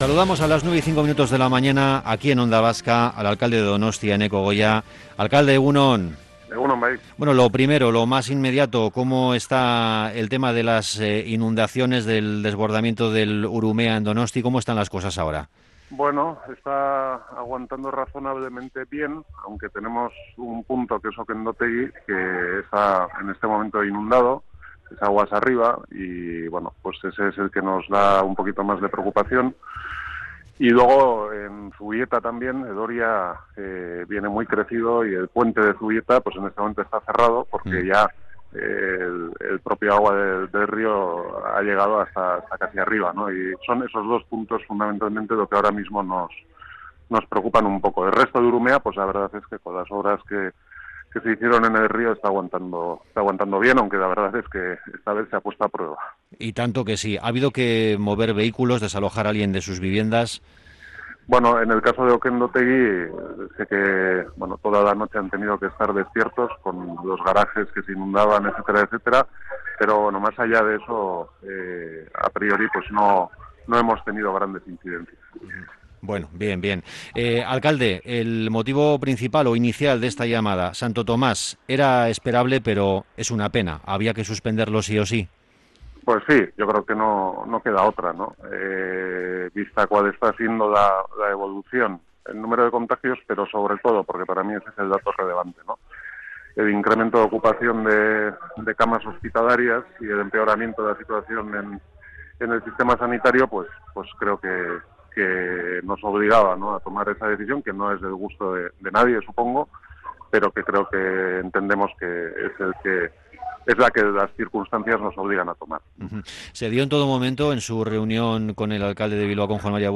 Hablamos con el alcalde de Donostia tras la suspensión de Santo Tomás y ante la decisión que habrá que tomar en próximos días sobre la bajada de Olentzero y la Cabalgata de reyes